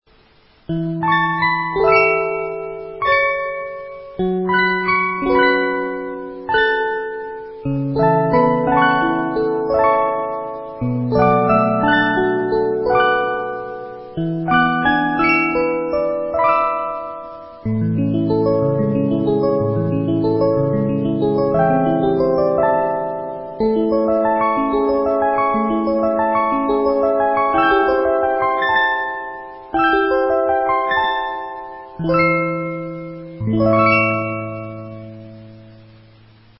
Sound Bites of the Most Popular 72 Note Movements